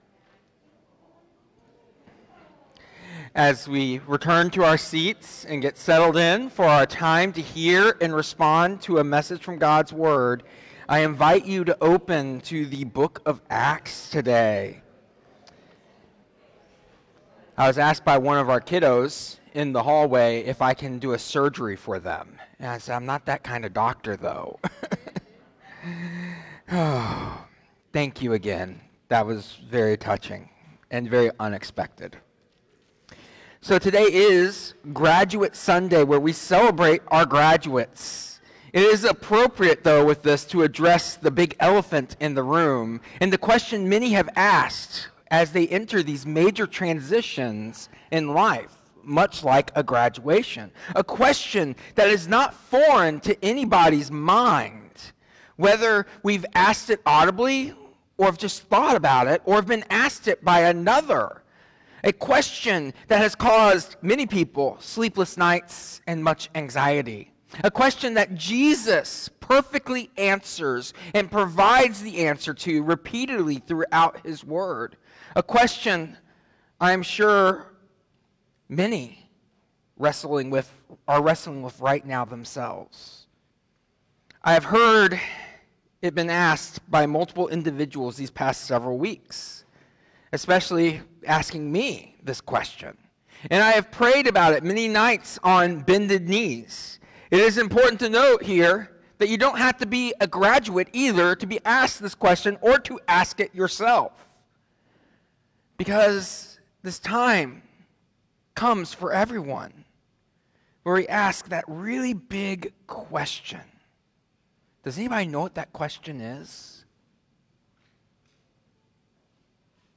Today’s message addresses the vital question of “What’s Next?” during a sermon celebrating graduates, emphasizing that this question is relevant to everyone facing transitions in life. It will highlight how the disciples asked a similar question during Jesus’ Ascension in Acts, underscoring the uncertainty that often accompanies significant life changes despite moments of certainty.